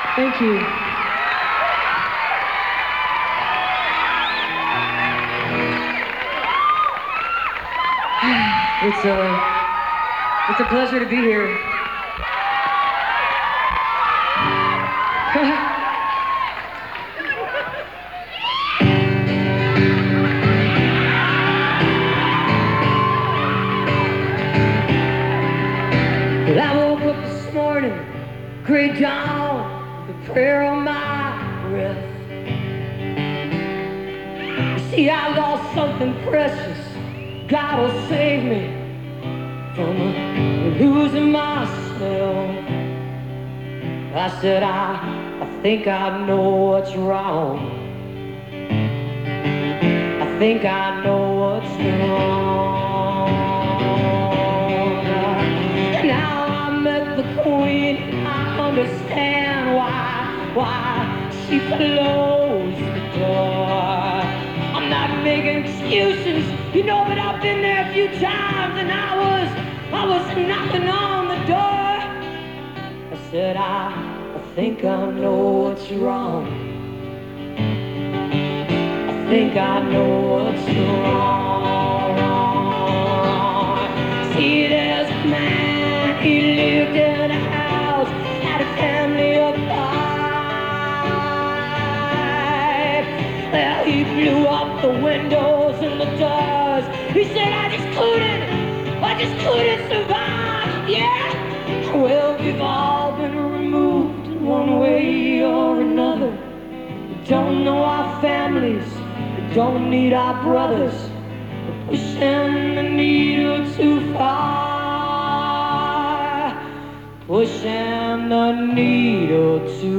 (acoustic duo show)
(radio broadcast)